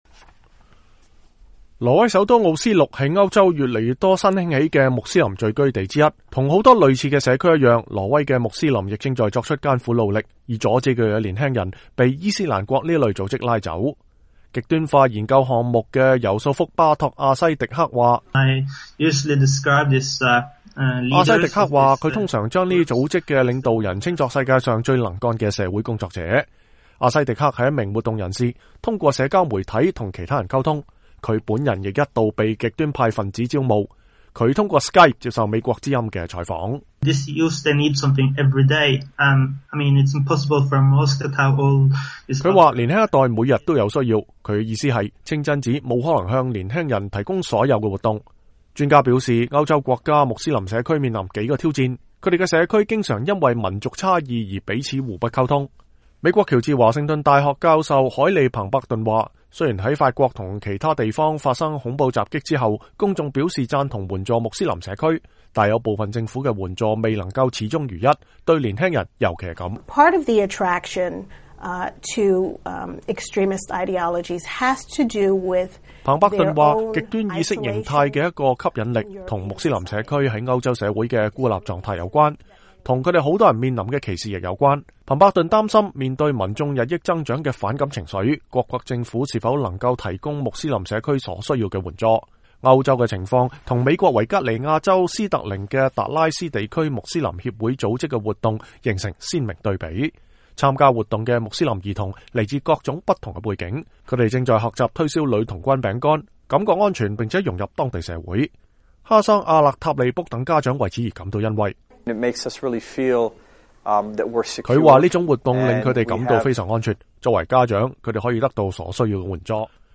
2015-02-18 美國之音視頻新聞: 歐洲穆斯林看好美國模式